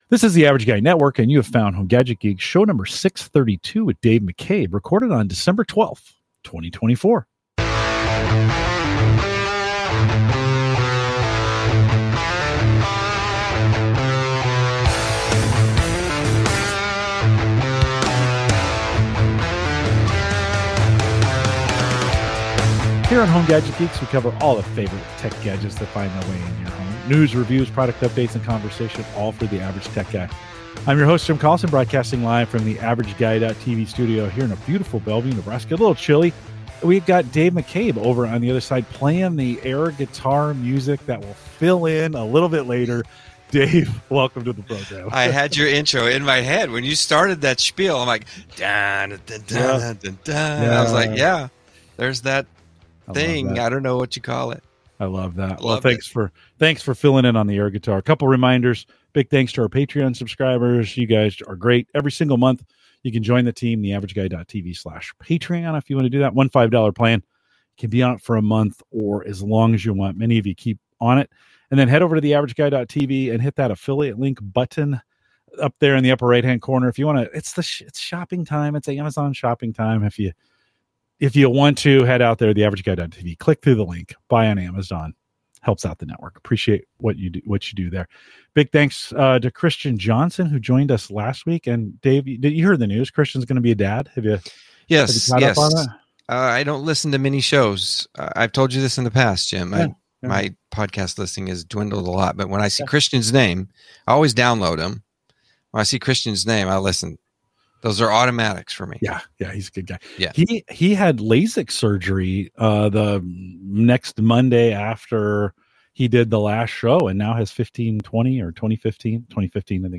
A Conversation on 3D Printing, Woodworking, and Home Automation